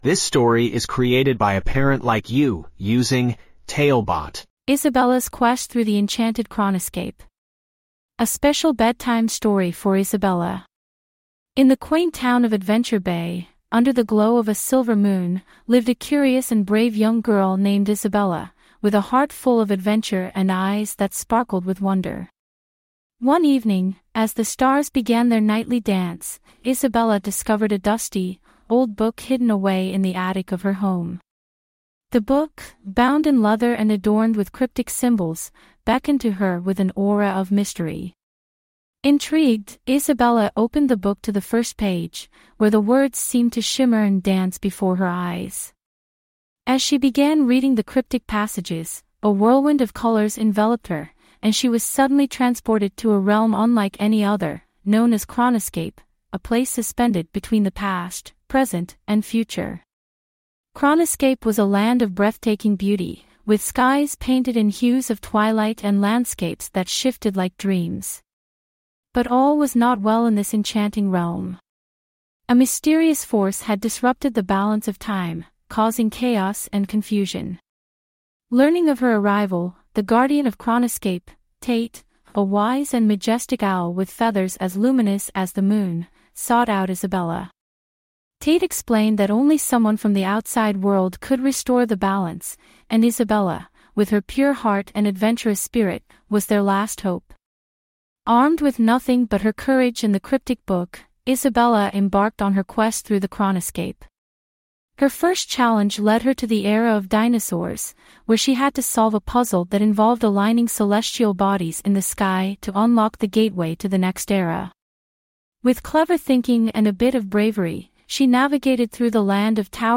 5 Minute Bedtime Stories
TaleBot AI Storyteller